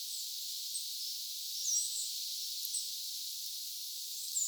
Kuusitiainen äänteli vähän kuin taigauunilintu.
kuusitiaisen taigauunilintumainen ääni
kuusitiaisen_taigauunilintumainen_aani.mp3